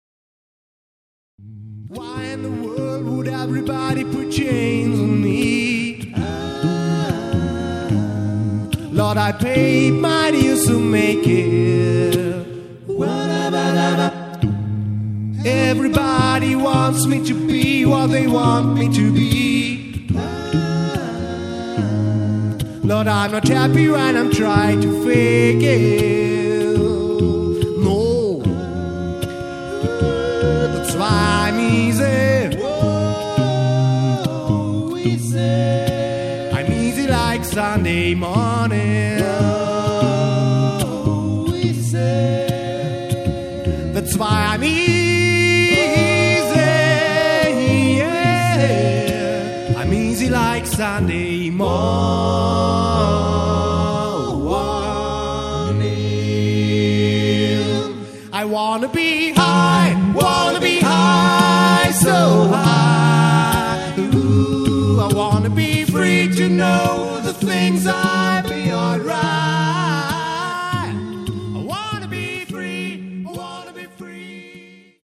A-Cappella
live & authentisch.